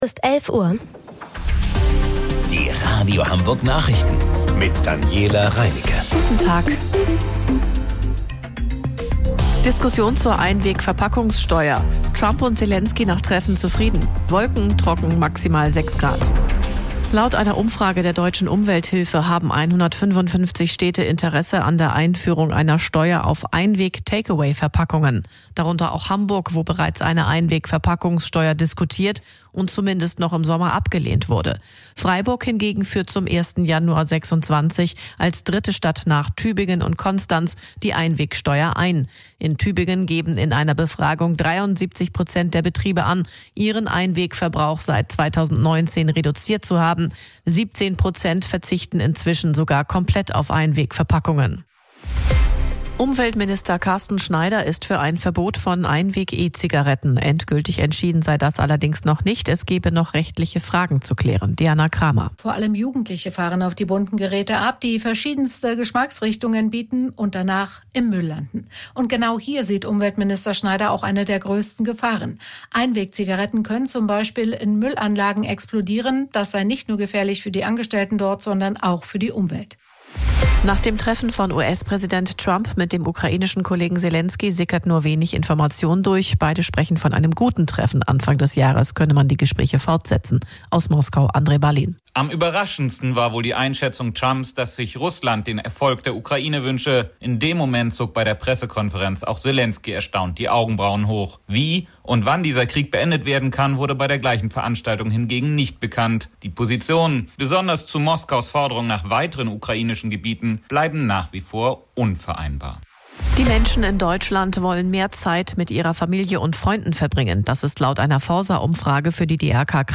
Radio Hamburg Nachrichten vom 29.12.2025 um 11 Uhr